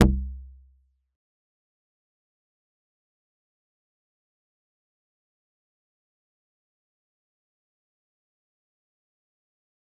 G_Kalimba-F1-mf.wav